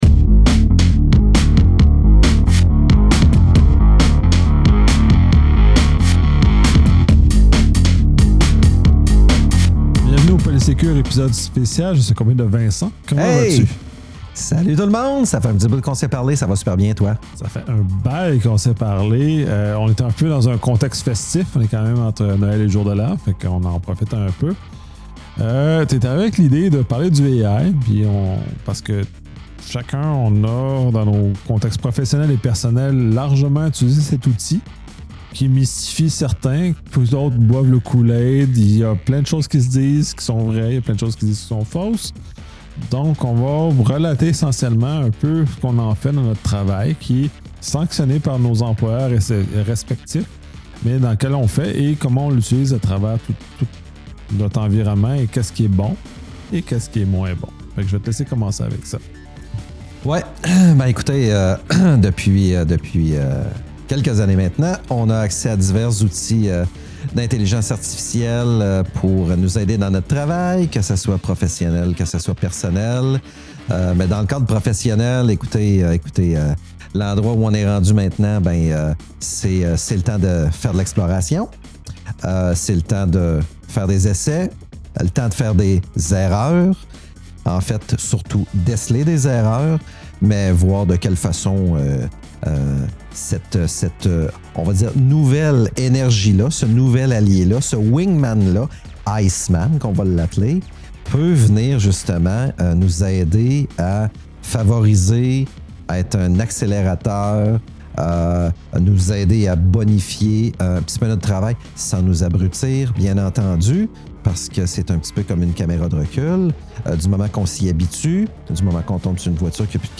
Dans cet épisode, les animateurs explorent l’utilisation concrète de l’intelligence artificielle dans leurs environnements professionnels respectifs. Enregistré dans un contexte festif entre Noël et le jour de l’An, ce podcast vise à démystifier l’IA en partageant des expériences réelles, sanctionnées par leurs employeurs, plutôt que de perpétuer des mythes ou des craintes infondées.